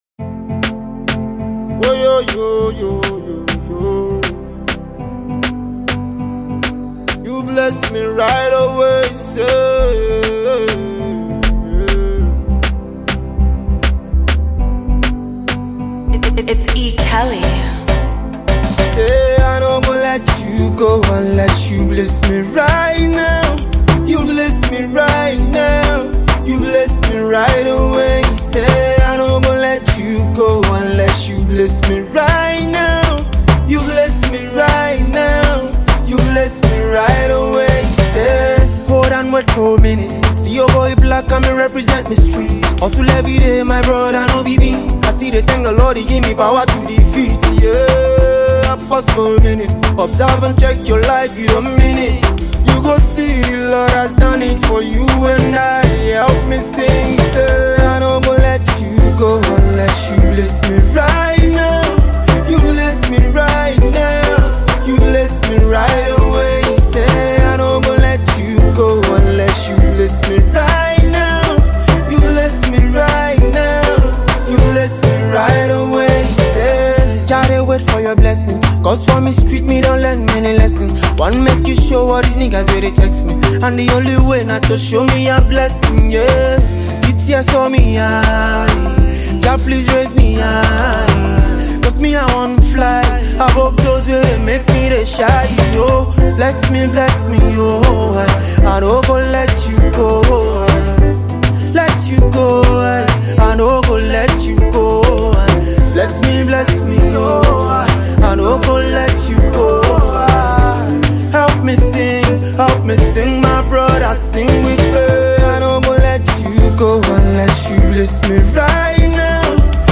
ragga tune